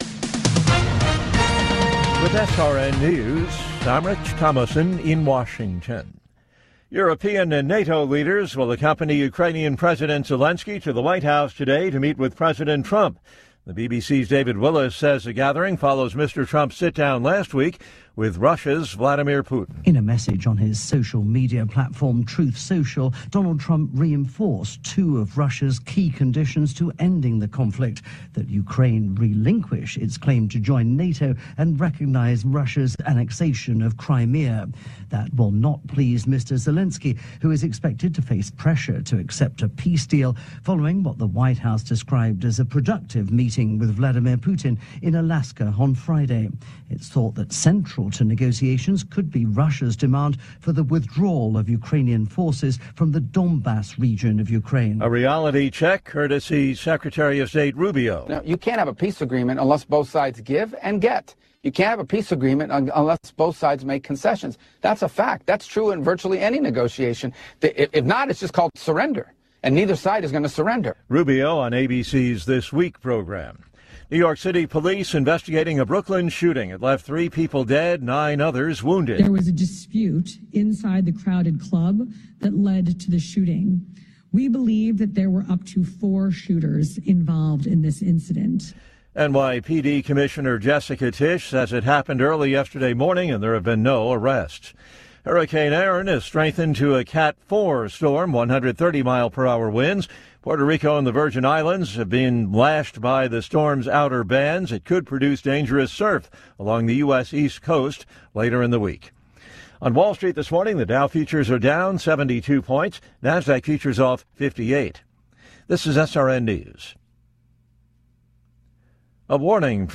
Top News Stories Aug 18, 2025 – 05:00 AM CDT